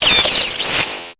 Cri de Raichu dans Pokémon Épée et Bouclier.